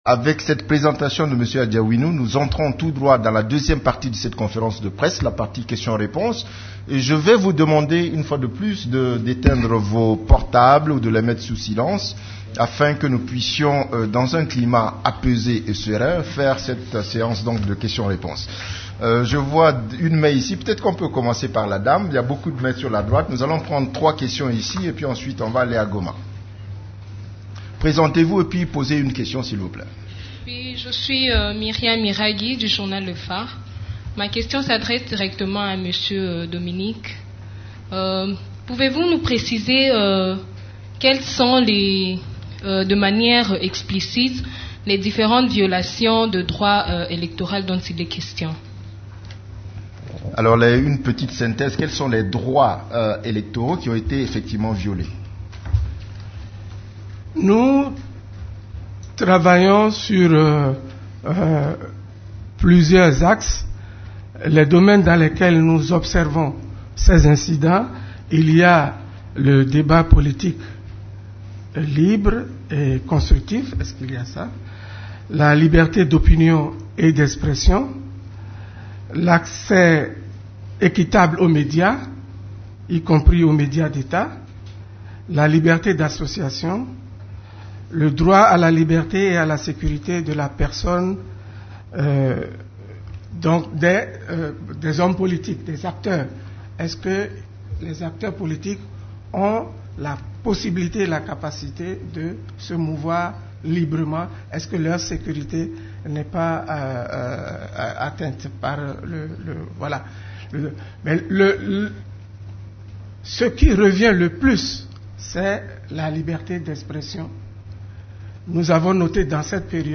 Conférence de presse du 7 octobre 2015
La conférence de presse hebdomadaire des Nations unies du mercredi 7 octobre a tourné autour des activités des composantes de la Monusco et celles des agences et programmes des Nations unies ainsi que de la situation militaire.